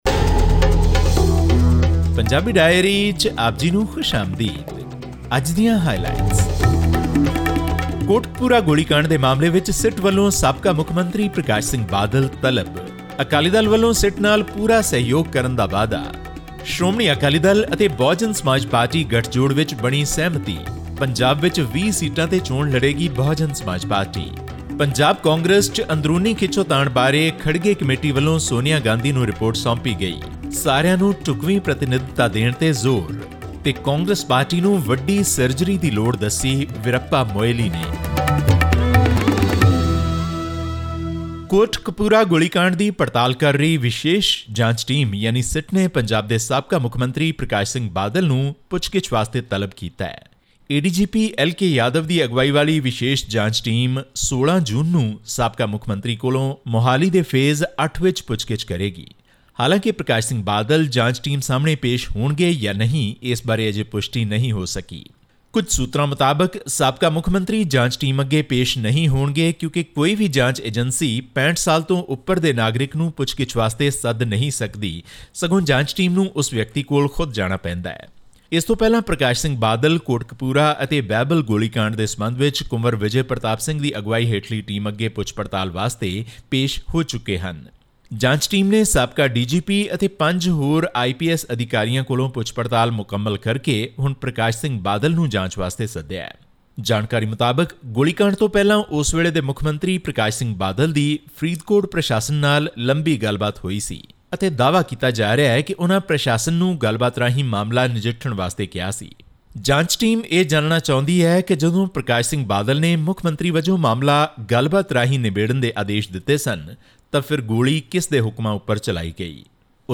The special investigation team (SIT) probing the Kotkapura police firing case has summoned Shiromani Akali Dal (SAD) patron Parkash Singh Badal on June 16. All this and more in this week's news bulletin from Punjab.